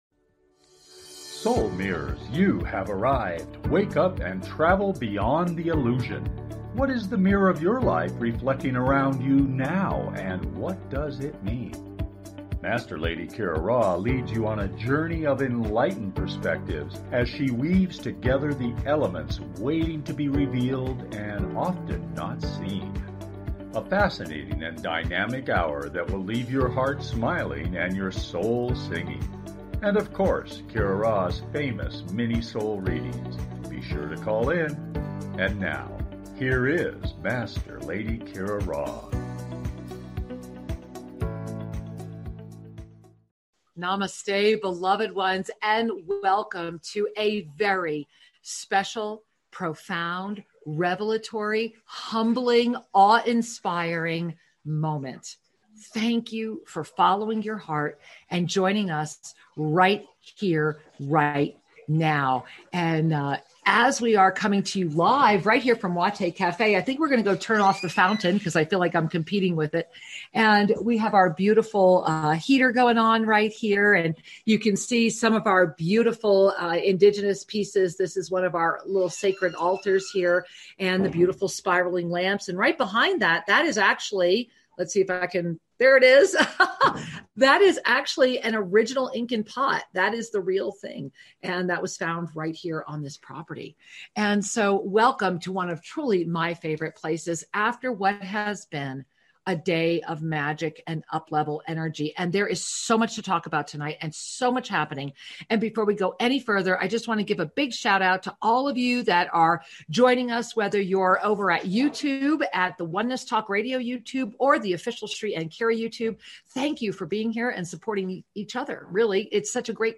Talk Show Episode, Audio Podcast, Soul Mirrors and Presence of the Divine Feminine, taking time out and getting in perfect balance on , show guests , about Presence of the Divine Feminine,taking time out,getting in perfect balance, categorized as Earth & Space,Health & Lifestyle,Kids & Family,Physics & Metaphysics,Psychology,Self Help,Society and Culture,Spiritual,Psychic & Intuitive